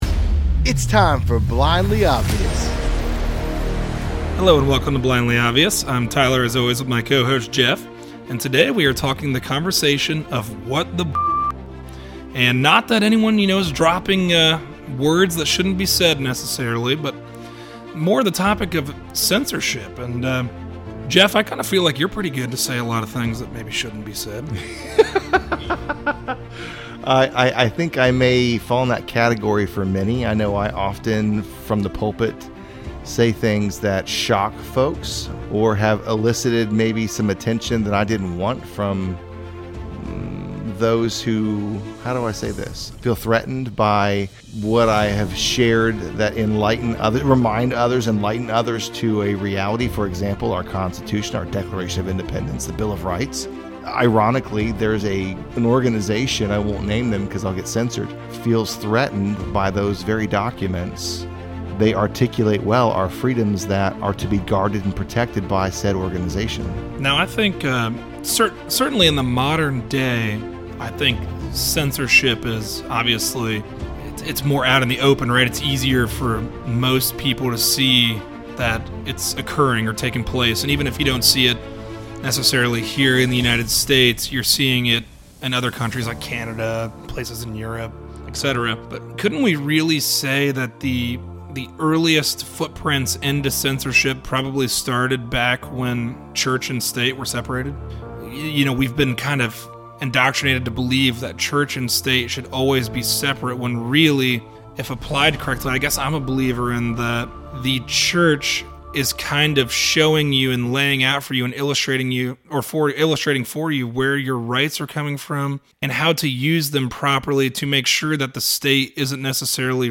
A conversation on censorship. Not only is censoring speech dangerous, but isn’t have a voice one of the key freedoms of our country?